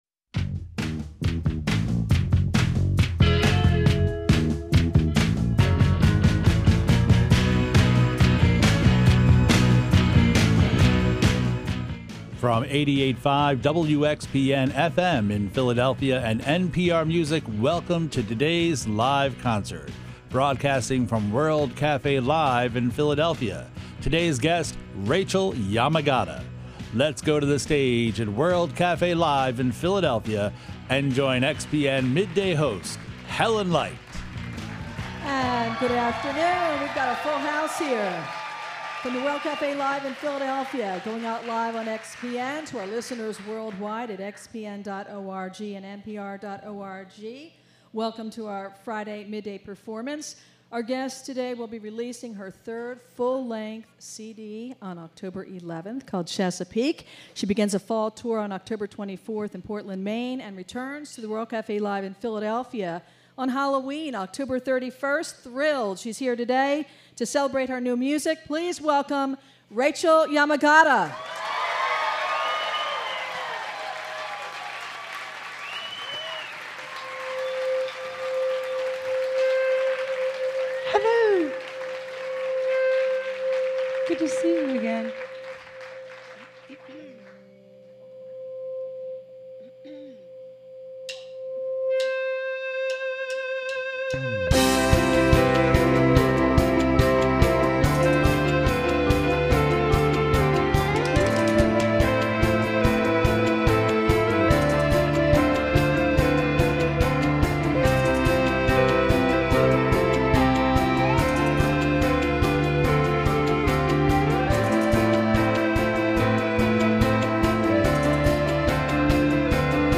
The sultry-voiced singer